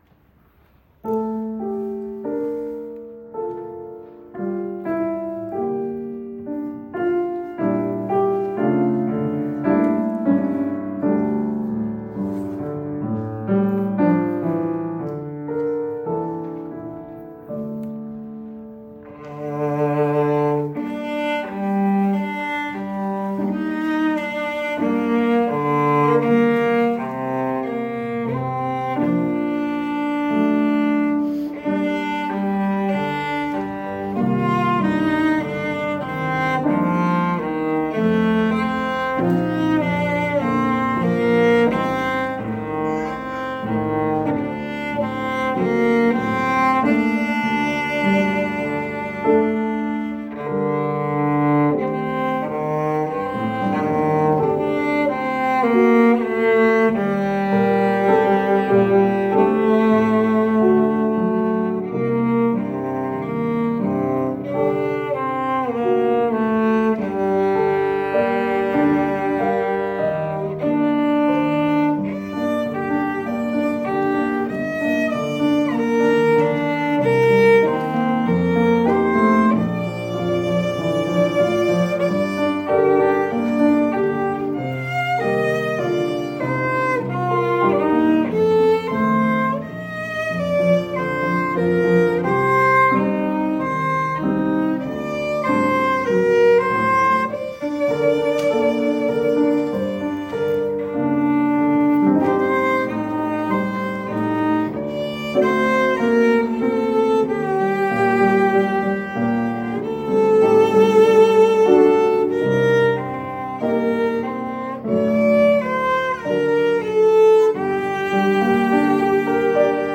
I also modified it slightly from the original by changing the octave of one section. This increased the range of notes used to 4 octaves! I grew in ability to play the high notes with a "fat" and relaxed sound (stopping the strings on their side instead of pressing them down to the fingerboard).